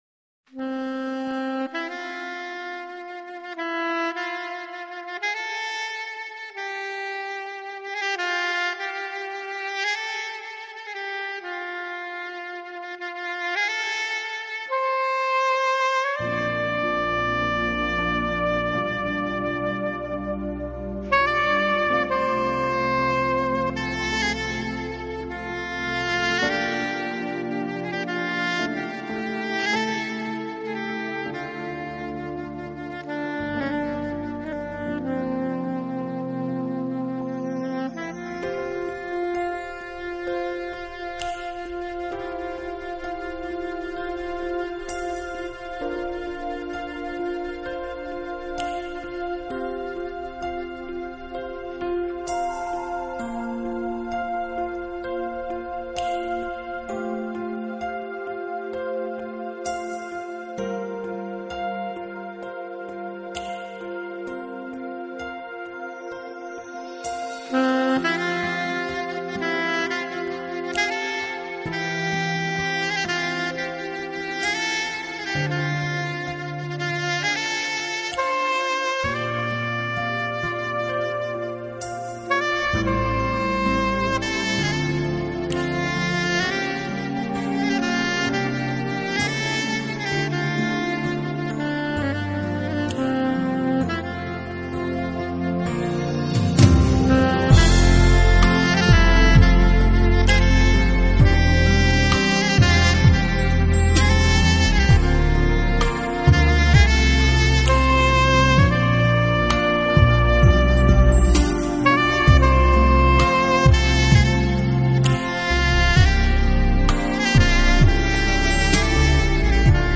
心，想要休息时，你需要与一支有灵魂的萨克斯风聊聊。